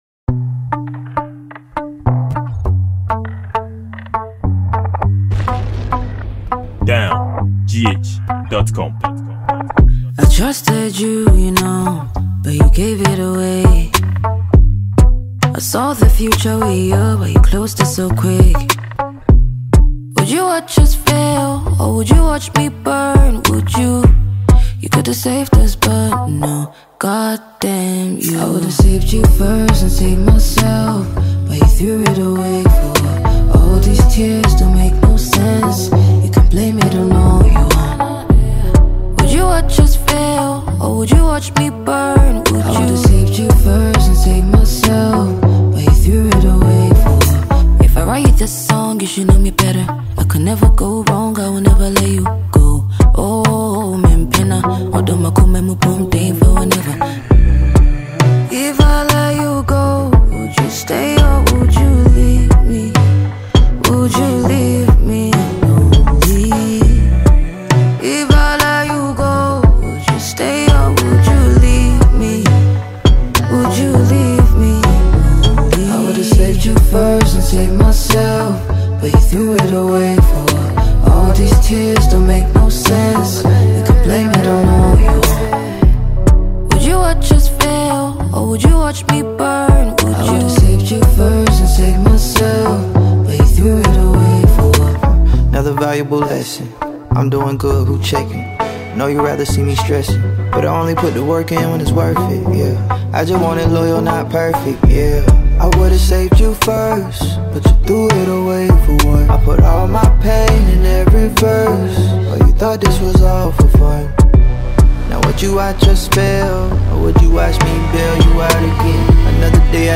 Ghana afrobeat song